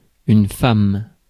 Ääntäminen
Ääntäminen : IPA : /ˈfleɪm/ US : IPA : [ˈfleɪm]